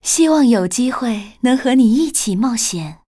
文件 文件历史 文件用途 全域文件用途 Dana_fw_02.ogg （Ogg Vorbis声音文件，长度3.1秒，101 kbps，文件大小：38 KB） 源地址:游戏语音 文件历史 点击某个日期/时间查看对应时刻的文件。 日期/时间 缩略图 大小 用户 备注 当前 2018年4月20日 (五) 02:45 3.1秒 （38 KB） 地下城与勇士  （ 留言 | 贡献 ） 源地址:游戏语音 您不可以覆盖此文件。